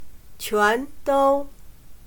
全都/Quándōu/Todos